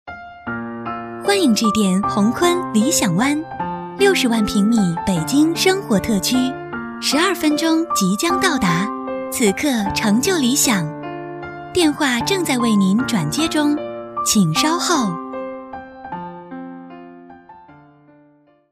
女声配音
彩铃女国89A